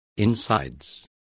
Also find out how tripas is pronounced correctly.